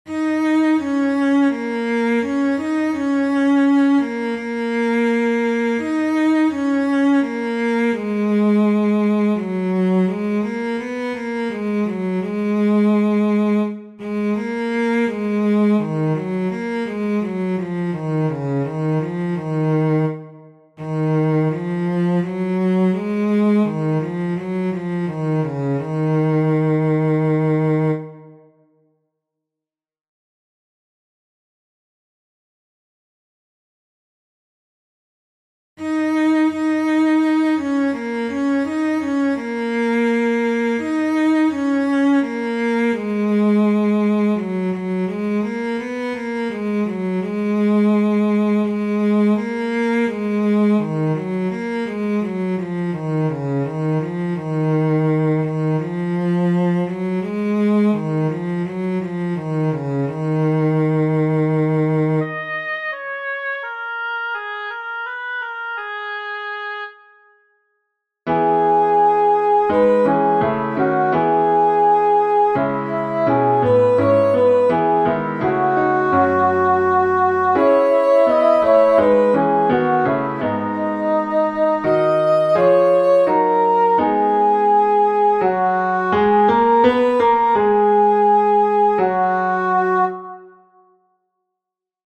Practice: The baritone soloist sounds like a cello. The choir enters after the oboe plays at 1:02.
five_mystical_songs_2.-soprano.mp3